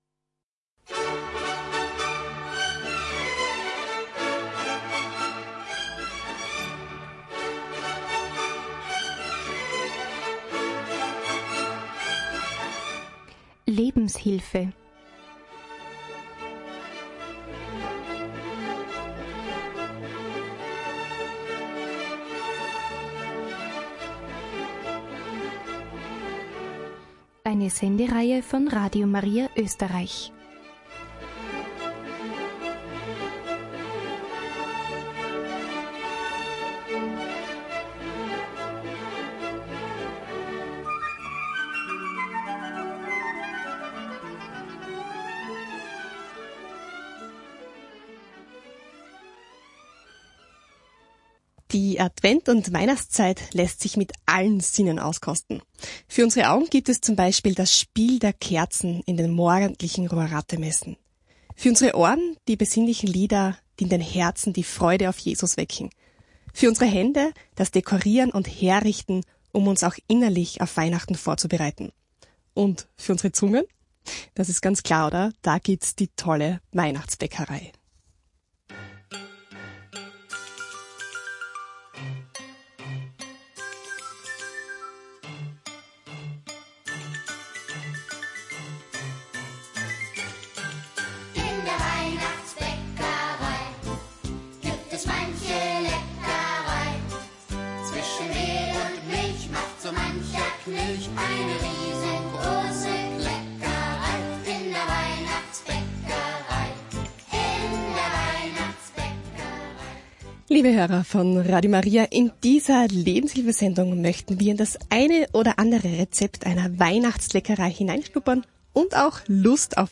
Weihnachtsbäckerei aus unserer Studioküche